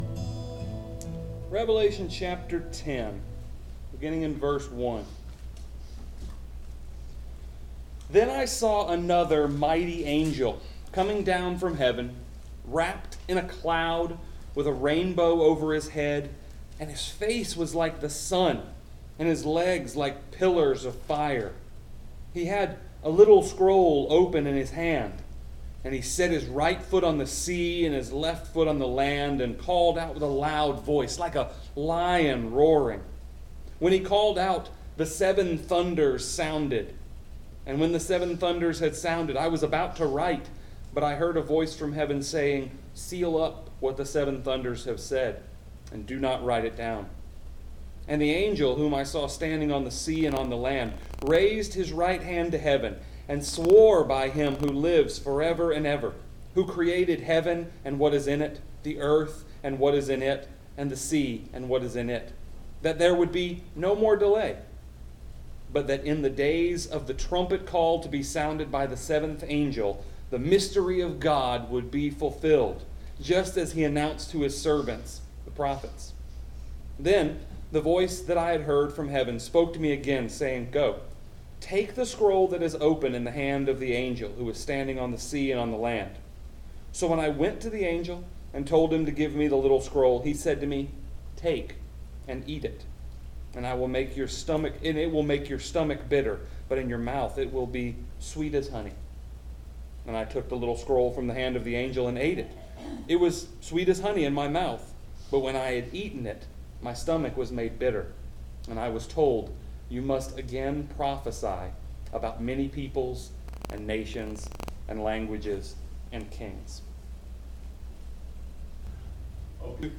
Passage: Revelation 10:1-7 Service Type: Sunday Evening